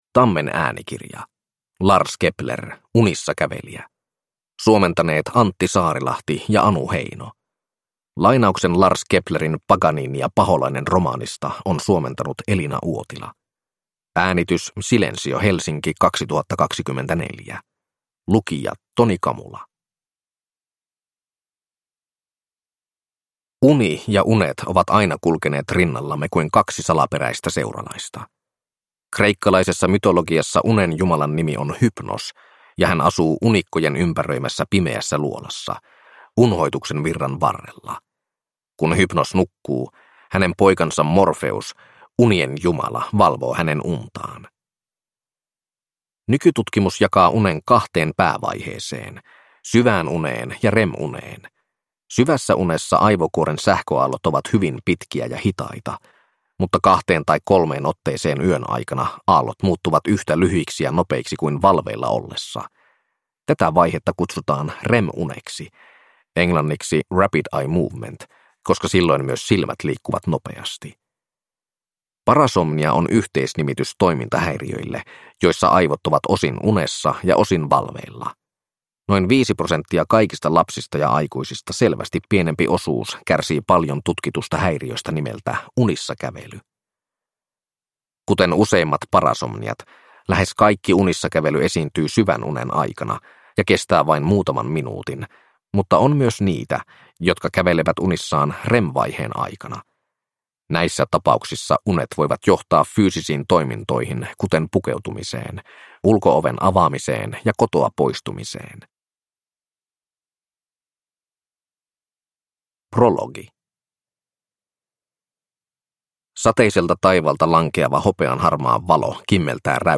Unissakävelijä – Ljudbok